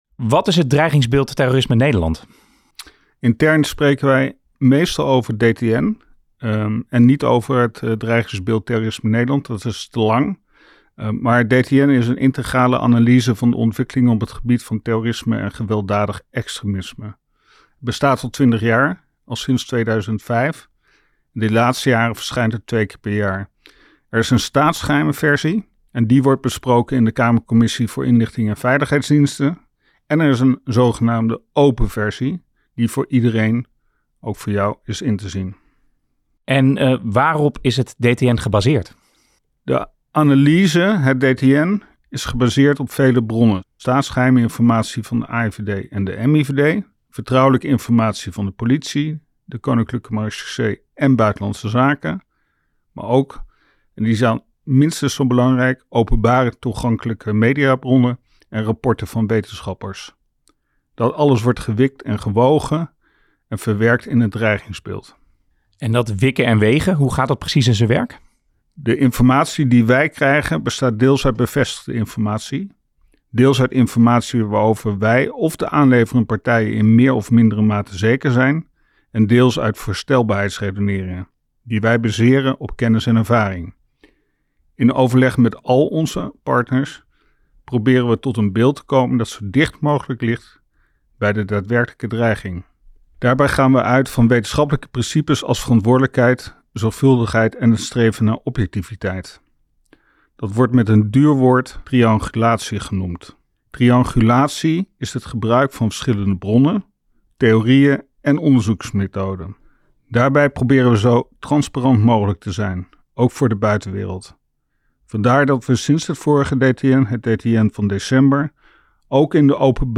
En wat betekenen die bevindingen voor het dreigingsniveau? Daarover spreken twee redacteuren van het DTN, werkzaam bij de afdeling Analyse Nationale Veiligheid van de NCTV.